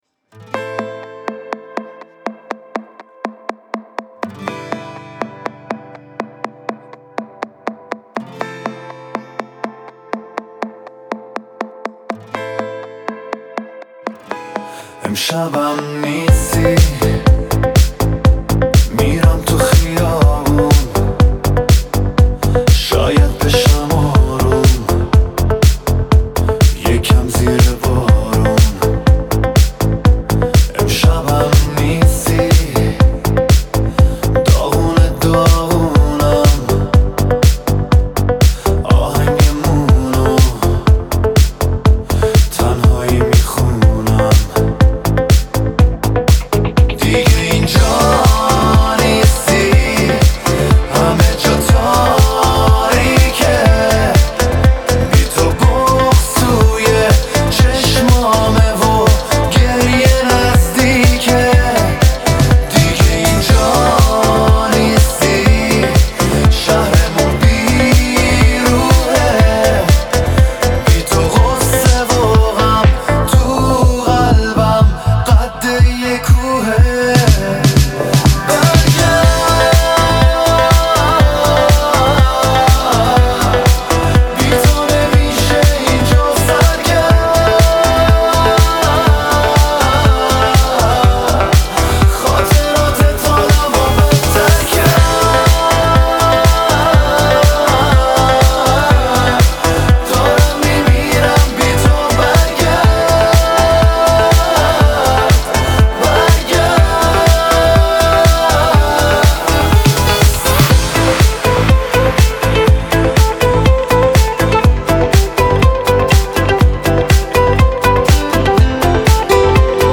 پاپ
آهنگ غمگین